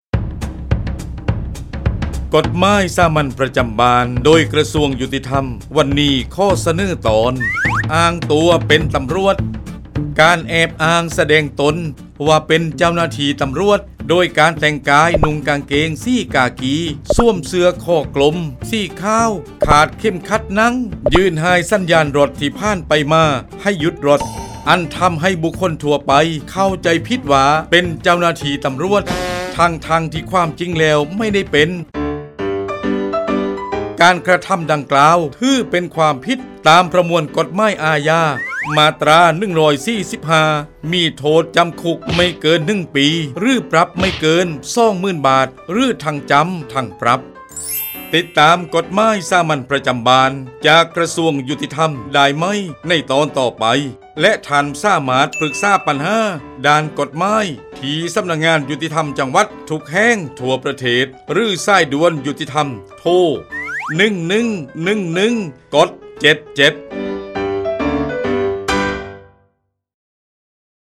กฎหมายสามัญประจำบ้าน ฉบับภาษาท้องถิ่น ภาคใต้ ตอนอ้างตัวเป็นตำรวจ
ลักษณะของสื่อ :   คลิปเสียง, บรรยาย